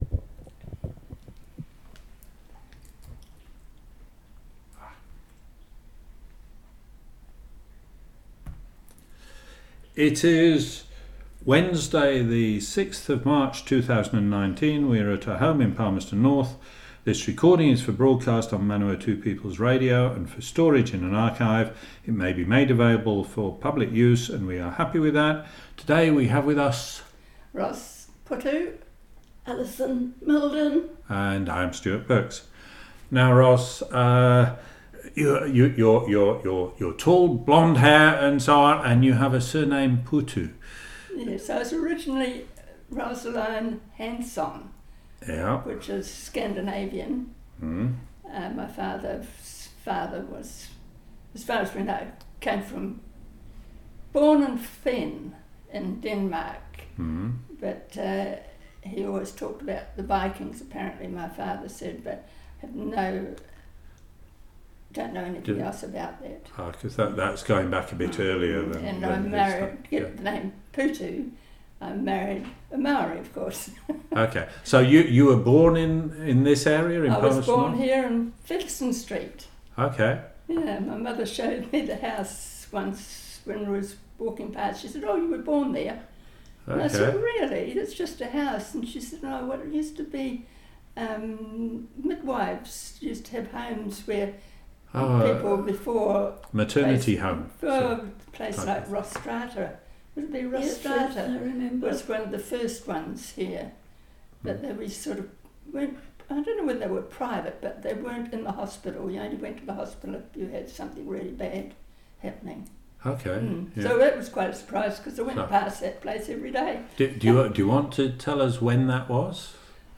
Broadcast on Manawatu People's Radio, 28 May 2019.